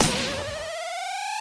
sniper_shot_02.wav